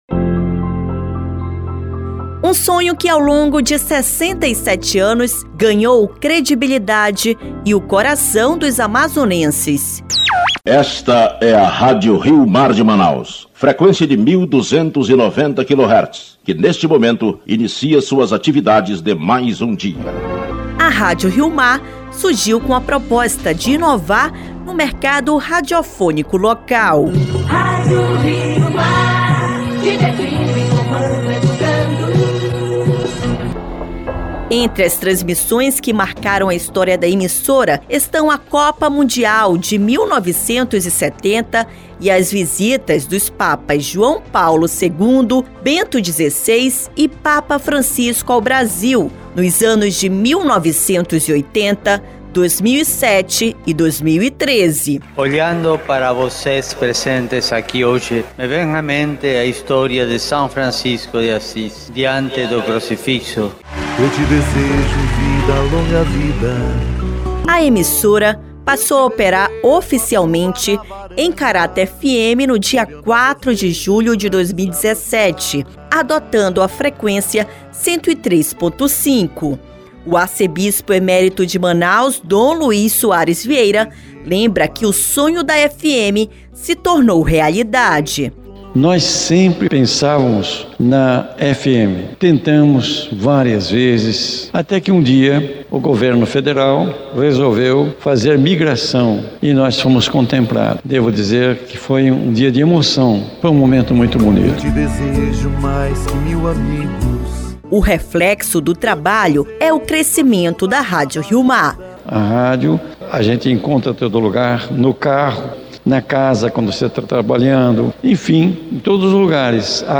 O arcebispo emérito de Manaus, Dom Luiz Soares Vieira relembra sua trajetória com a emissora e como o sonho da FM se tornou realidade.
O ex-prefeito de Manaus, Arthur Virgílio Neto lembra da sua história com a emissora.
O deputado estadual e ex-prefeito de Manaus, Serafim Corrêa também fez parte da história da rádio.